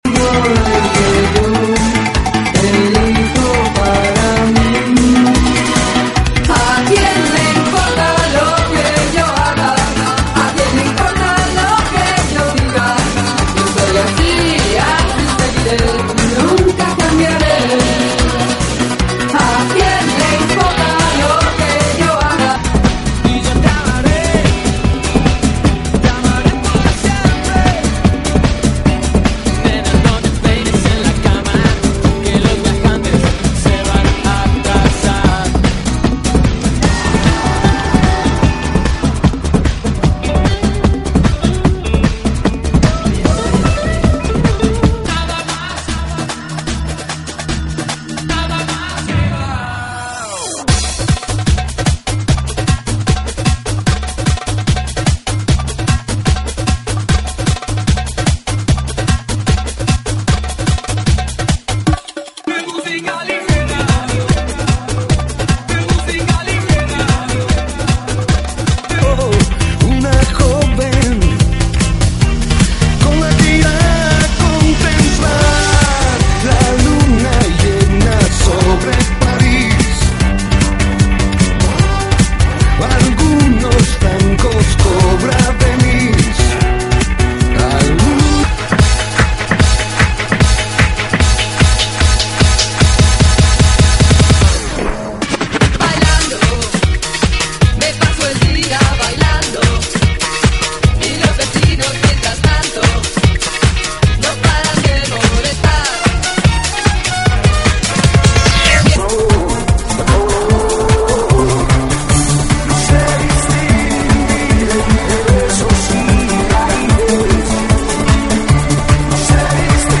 GENERO: DANZAS TRIBALES – LATIN
DANZAS TRIBALES, LATINO,